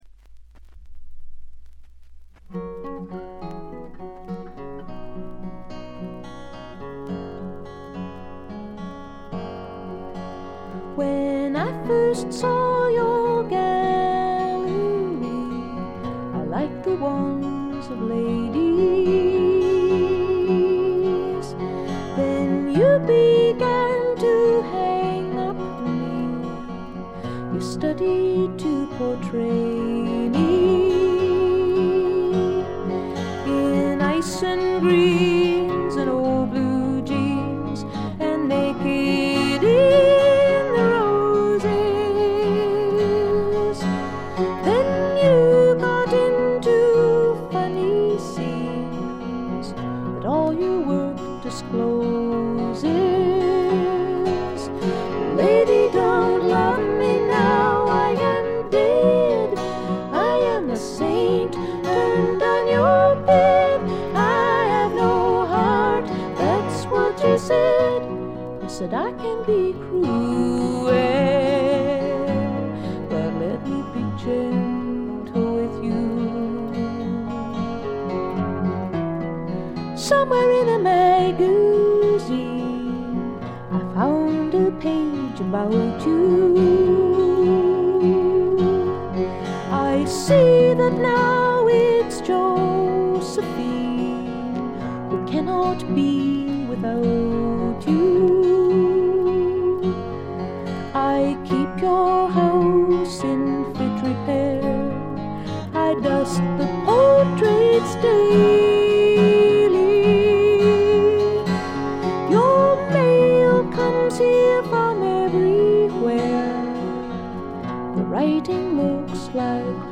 B1無音部から冒頭少しチリつき。
これ以外は微細なバックグラウンドノイズ程度。
透明感のあるみずみずしさが初期の最大の魅力です。
女性フォーク／シンガーソングライター・ファンなら避けては通れない基本盤でもあります。
試聴曲は現品からの取り込み音源です。